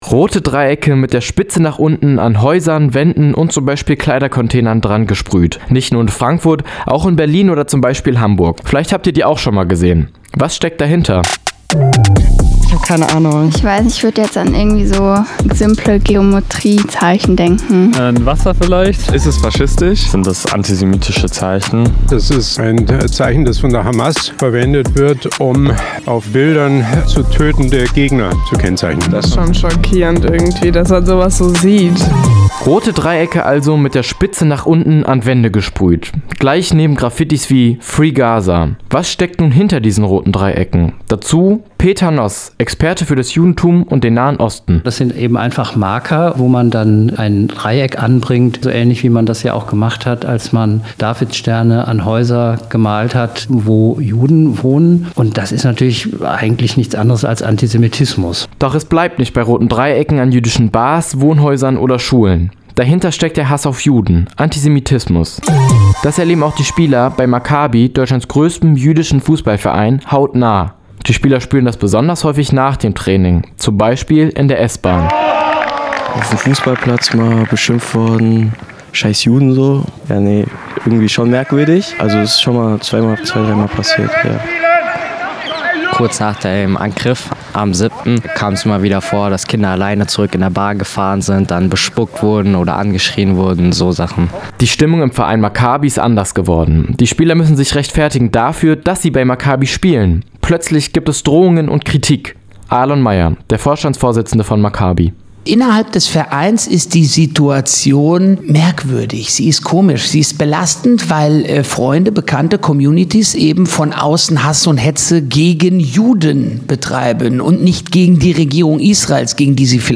spricht mit einem Experten